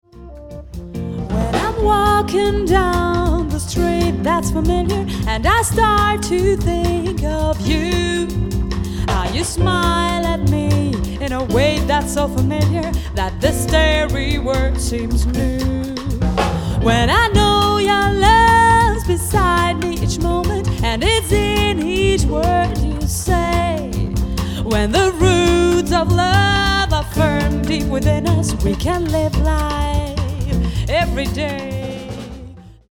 Jazz Sängerin & Songwriterin